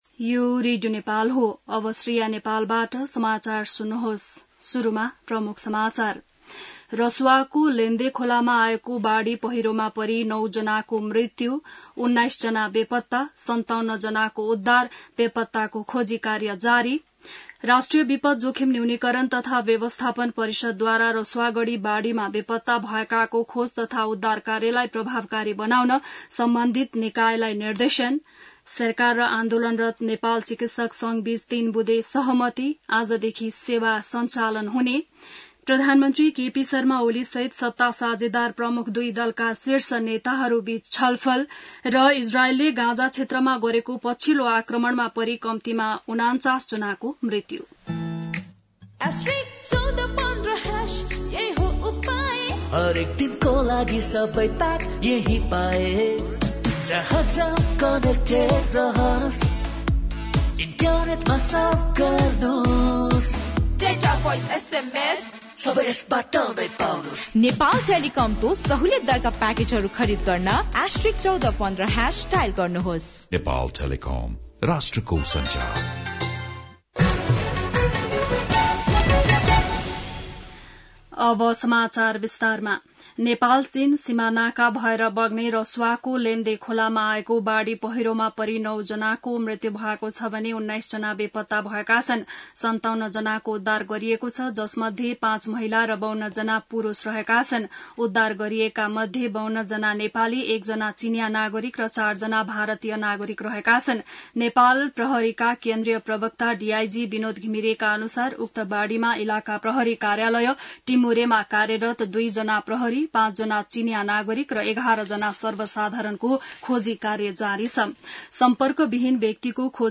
बिहान ७ बजेको नेपाली समाचार : २५ असार , २०८२